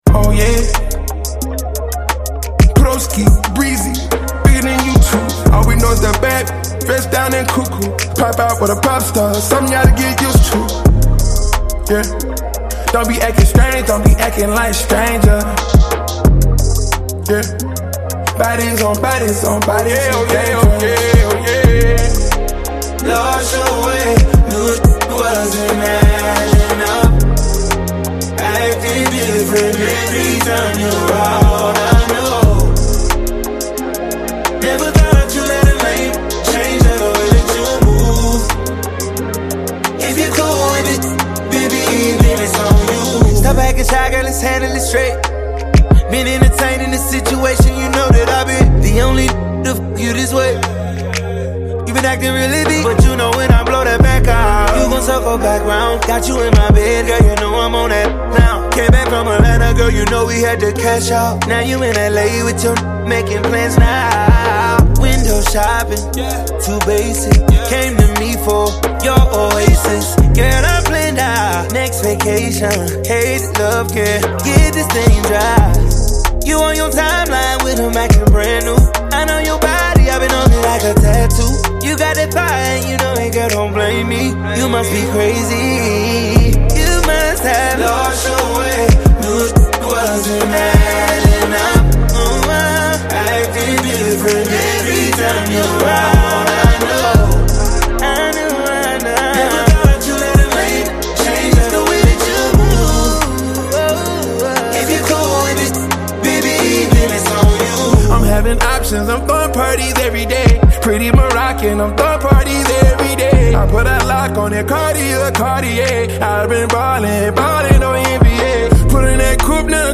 R&B, pop, and smooth beats